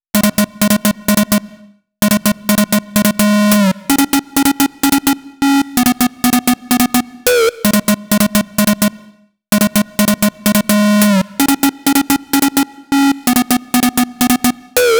VTDS2 Song Kit 09 Rap Let's Move Synth Classic.wav